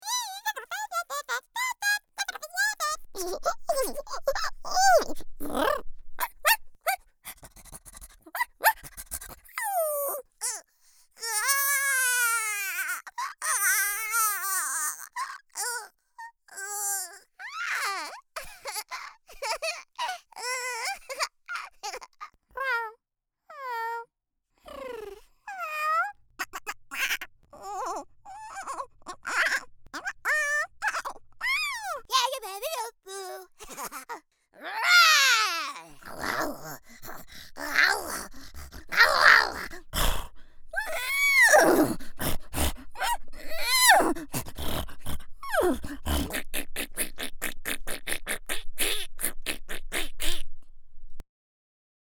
Voiceover
Creature